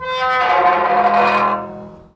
file0543 车库门打开和关闭
描述：电动车库门开启器打开门，然后关闭门。
标签： 现场记录 车库
声道立体声